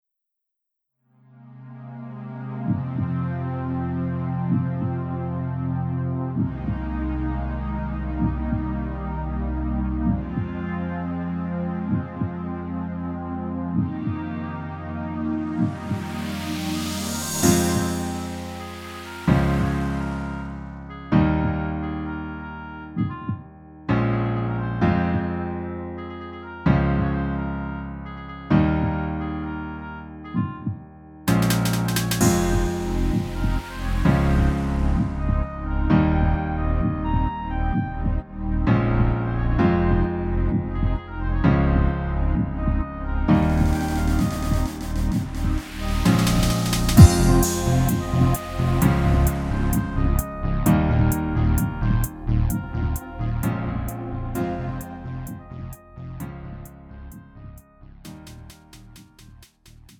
음정 -1키
Lite MR